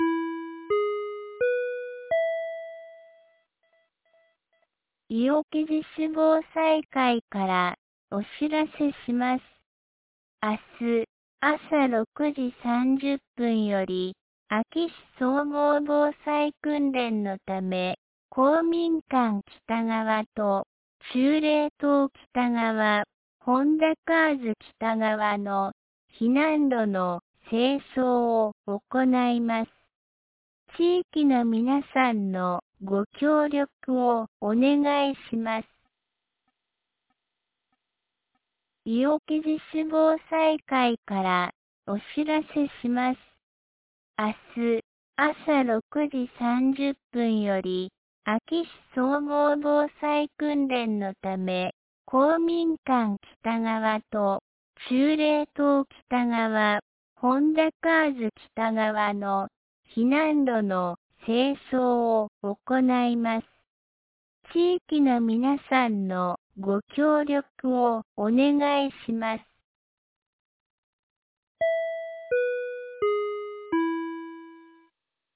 2025年09月05日 17時21分に、安芸市より伊尾木へ放送がありました。